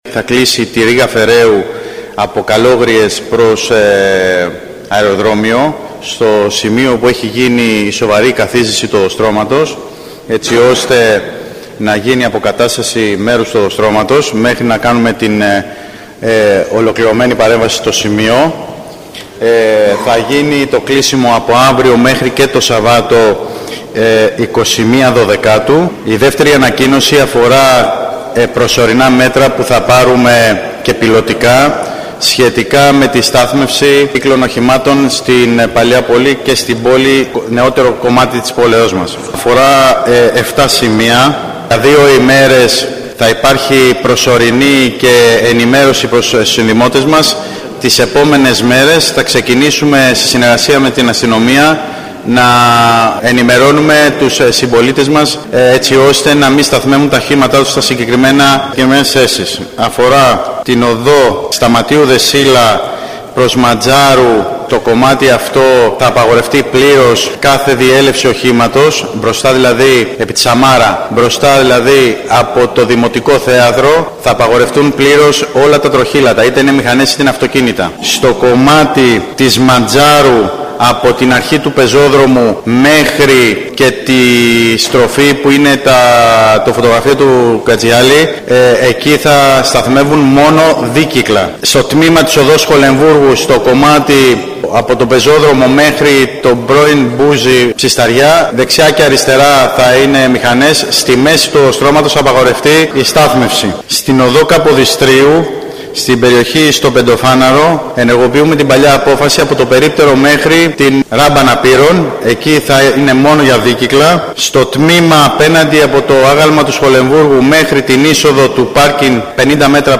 Τα νέα κυκλοφοριακά μέτρα ανακοίνωσε ο αντιδήμαρχος Τεχνικών Υπηρεσιών Νίκος Καλόγερος. Η οδός Ρήγα Φεραίου στις Καλόγριες θα κλείσει προκειμένου να γίνουν εργασίες από σήμερα μέχρι το Σάββατο 21 Δεκεμβρίου. Επίσης ανακοίνωσε τα προσωρινά μέτρα που θα ρυθμίσουν τη στάθμευση των μοτοσυκλετών στο κέντρο της πόλης, όπου ορίζονται συγκεκριμένα σημεία και θα απαγορεύεται στα υπόλοιπα.